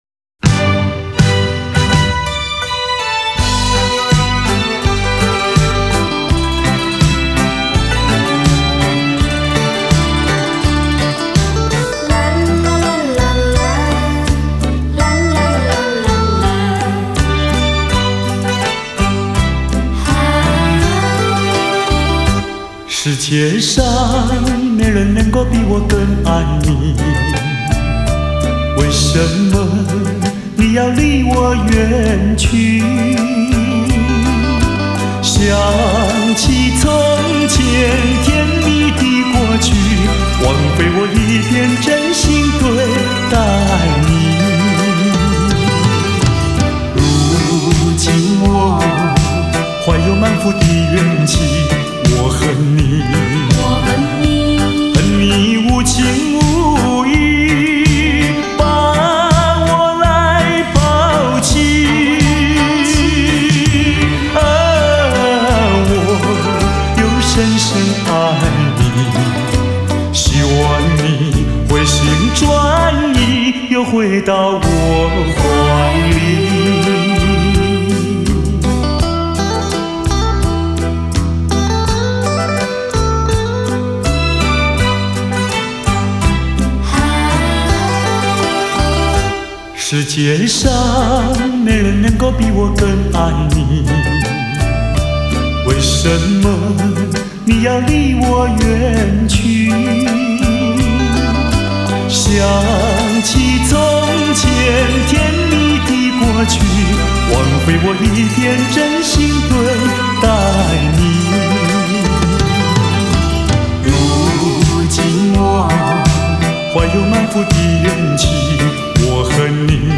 样本格式    : 44.100 Hz;16 Bit;立体声